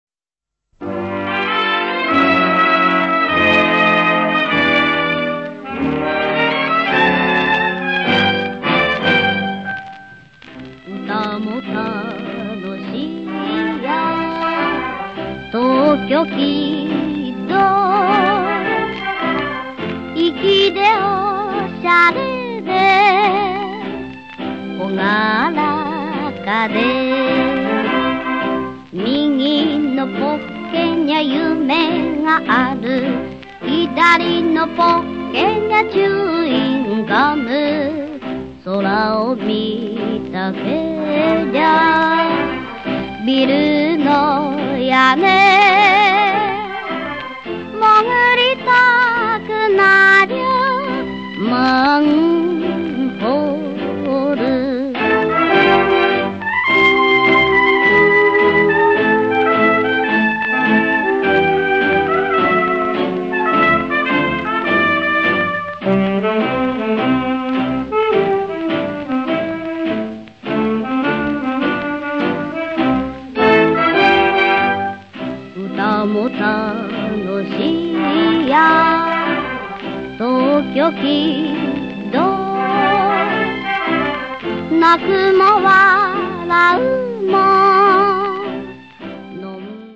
Japanese elements are mixed with boogie woogie
in what was an early world music mixture on CD 1.
CD 2 has a lot of jazz influences